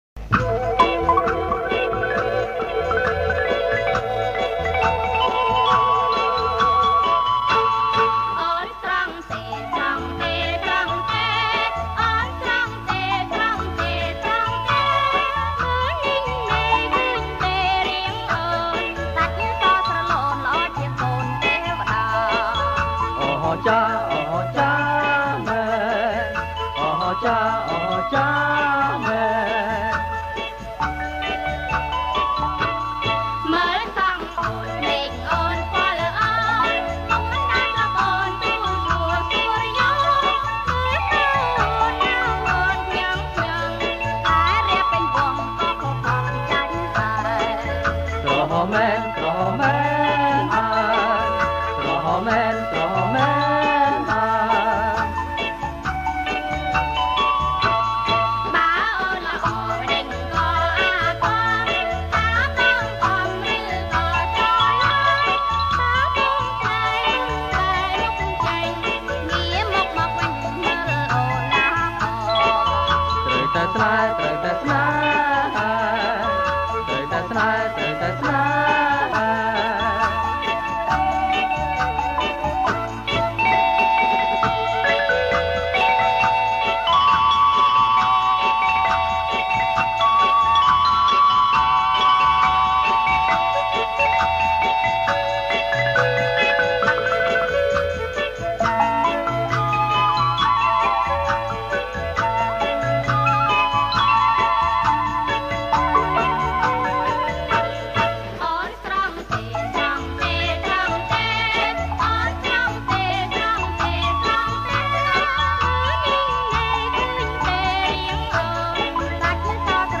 • ប្រគំជាចង្វាក់ ឡាំលាវ